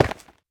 Minecraft Version Minecraft Version 25w18a Latest Release | Latest Snapshot 25w18a / assets / minecraft / sounds / block / basalt / break1.ogg Compare With Compare With Latest Release | Latest Snapshot